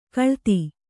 ♪ kaḷti